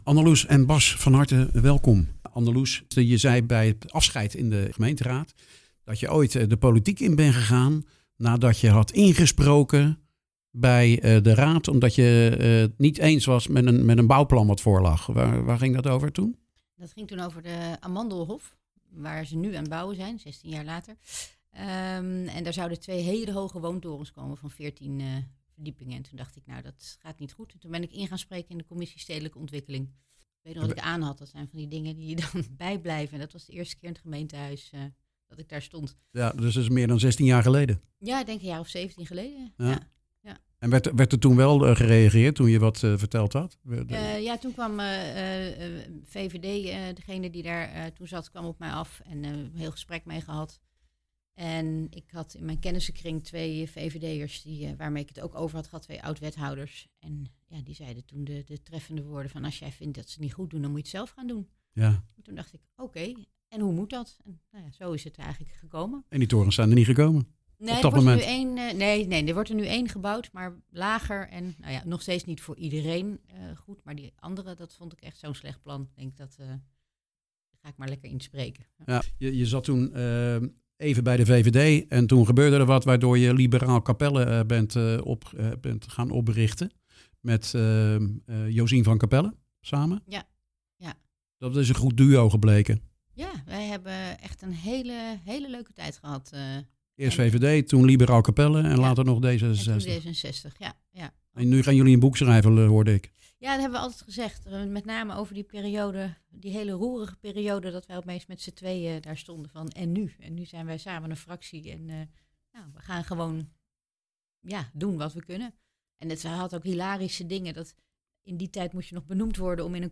De afgelopen twee weken hebben we nieuwe raadsleden in de studio gehad, vandaag twee ex-raadsleden die bij elkaar 28 jaar in de raad hebben gezeten.